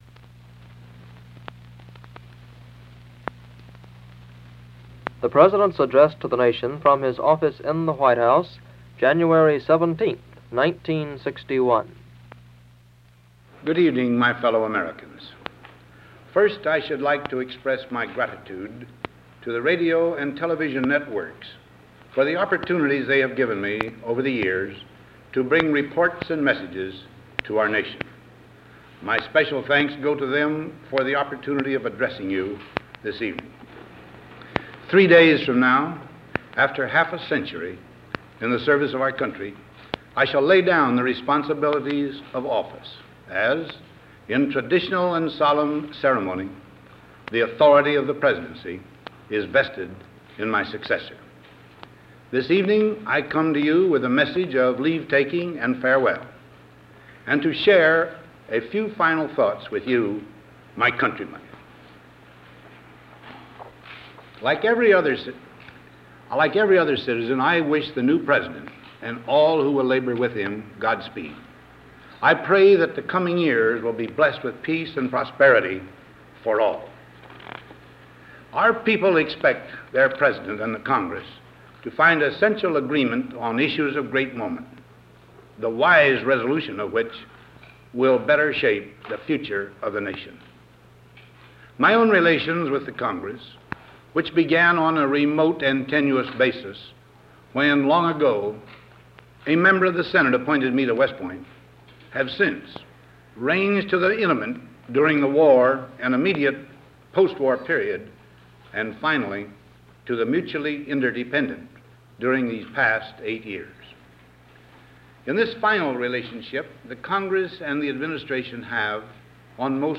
Presidential Speeches | Dwight D. Eisenhower